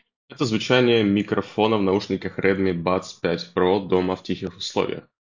Микрофон Redmi Buds 5 Pro на 7.5 из 10. Немного подкачал в шумных условиях возле трассы, но дома — качестве шикарное:
В тихих условиях (9/10):